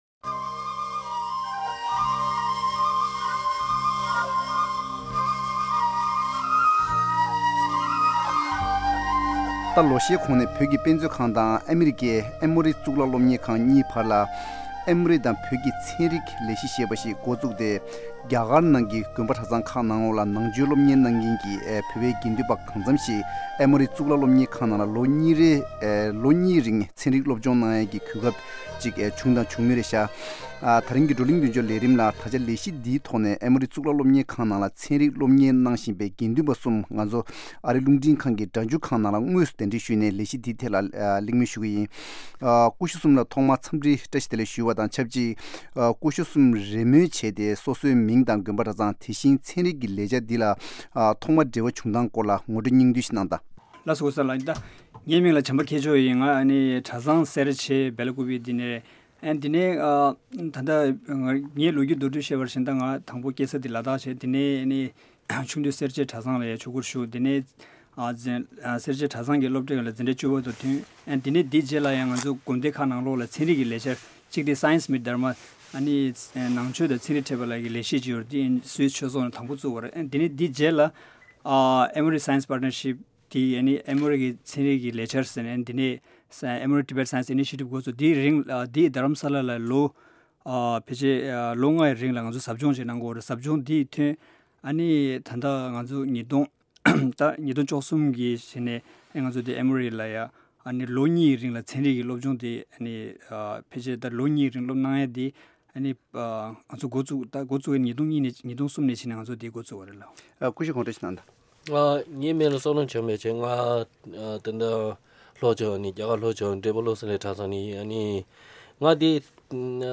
After two years of intensive science education in Emory, many monks have returned to their respective monasteries to teach other monks. Table Talk invites three monks who are studying science in Emory Universit